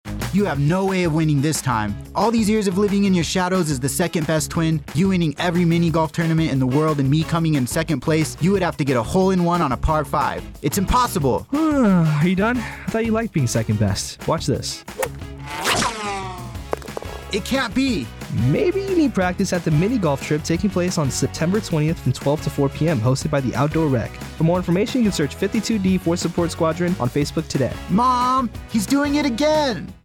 30 second spot on the mini golf event hosted at the outdoor rec.